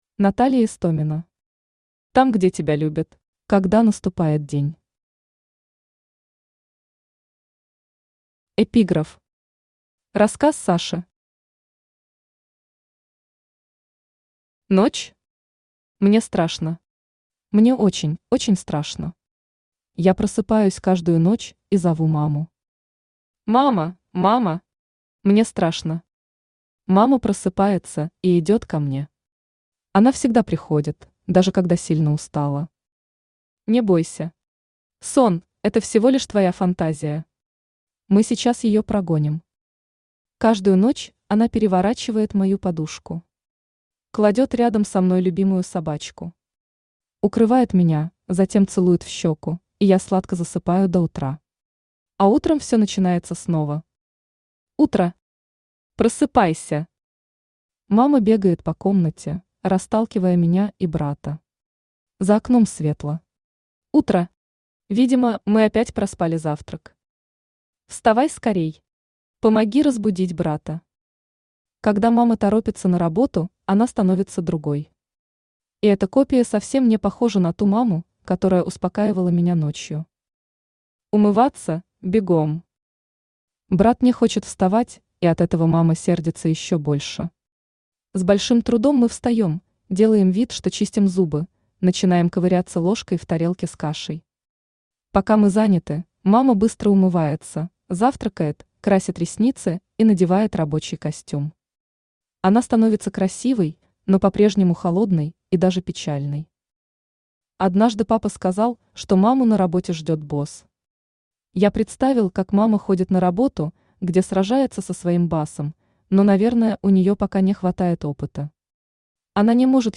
Аудиокнига Там, где тебя любят | Библиотека аудиокниг
Aудиокнига Там, где тебя любят Автор Наталья Истомина Читает аудиокнигу Авточтец ЛитРес.